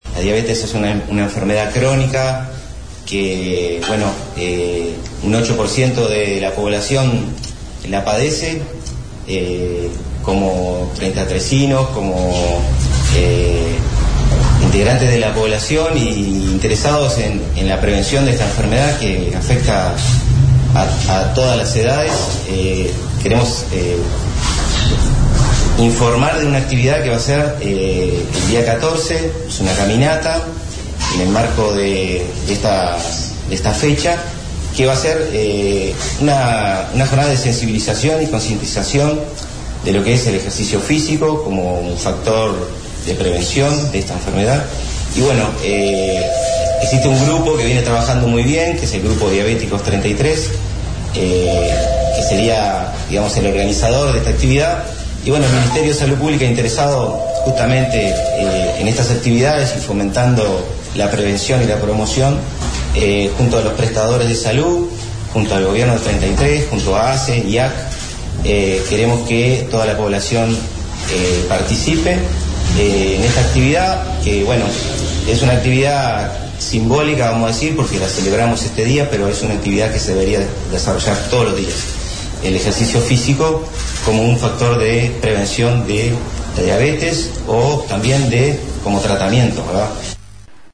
Será en el Polo Deportivo con concentración media hora, donde se deben presentar con distintivo de color azul y se encenderán luces de ese color sobre ruta 8 a la entrada de la ciudad de Treinta y Tres, donde se encuentran las letras de la ciudad, según explicó en conferencia de prensa el director de salud, Nelson Alvez.
Informe